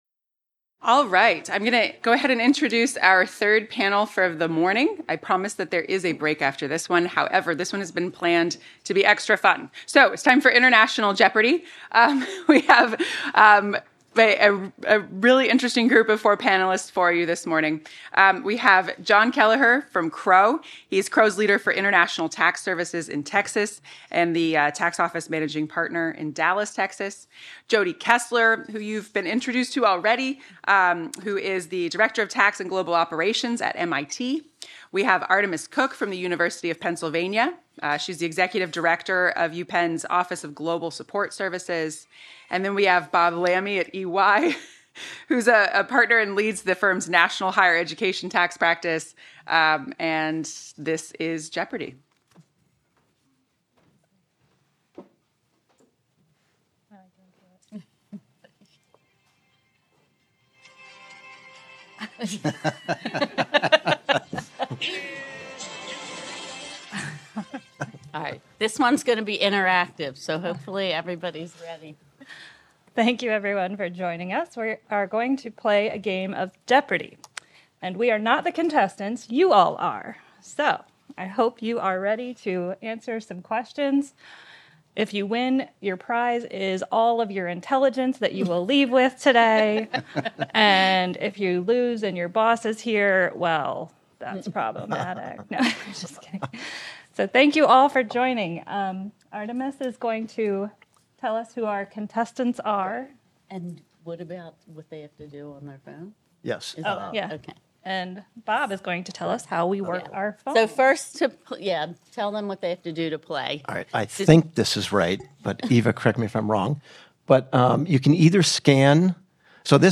Originally presented: Jun 2024 Higher Education Taxation Institute